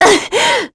Scarlet-Vox_Damage_02.wav